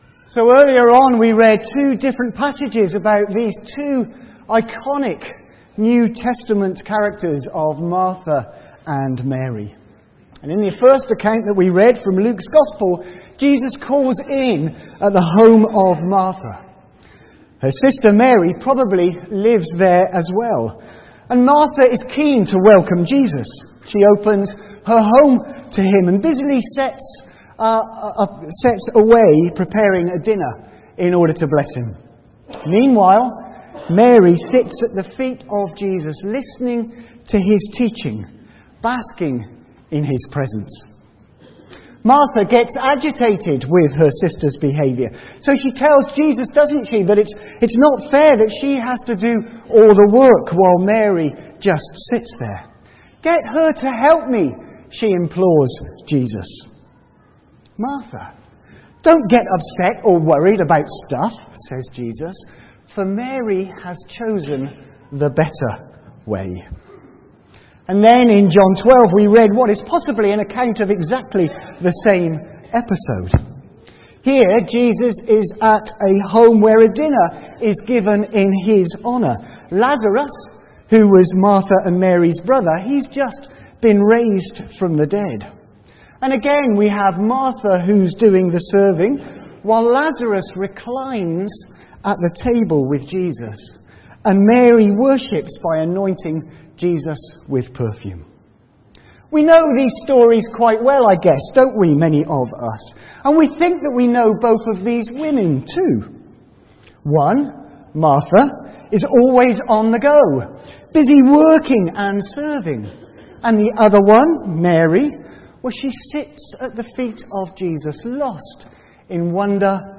A message from the series
From Service: "10.00am Service"